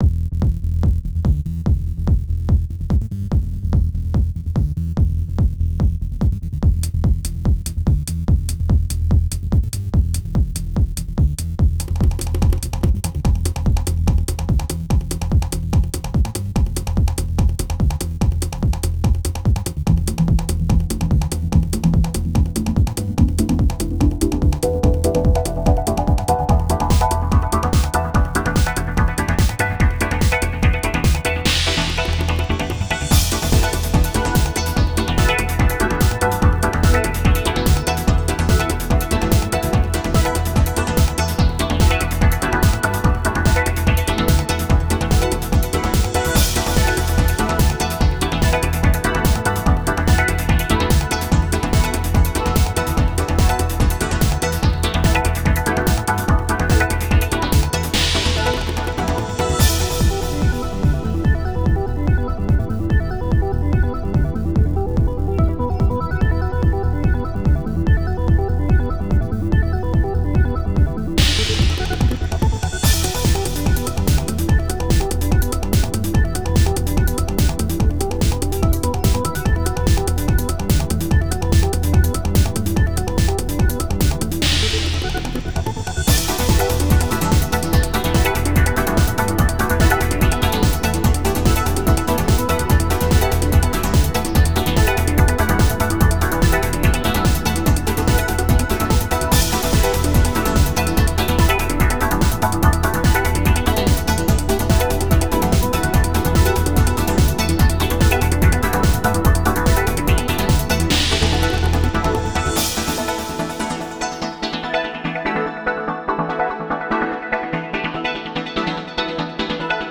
Techno
I love techno, so here's some pure techno goodness!
Very velvety, very smooth, very energetic.